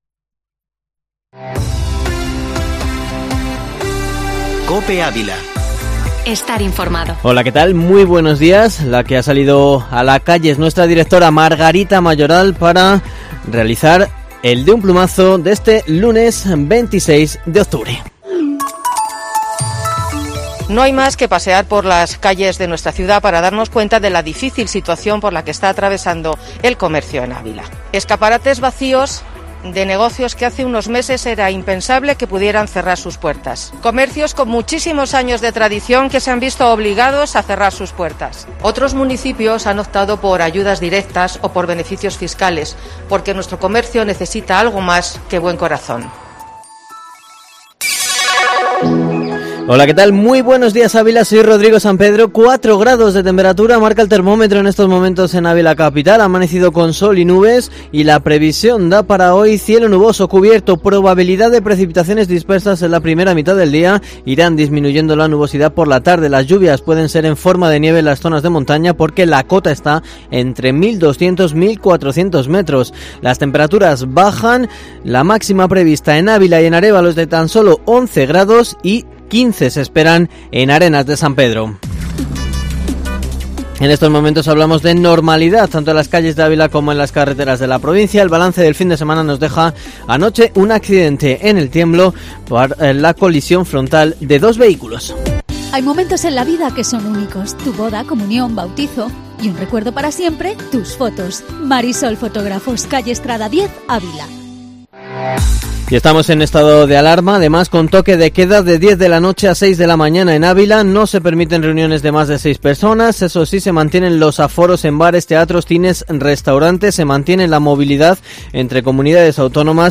Informativo matinal Herrera en COPE Ávila 26/10/2020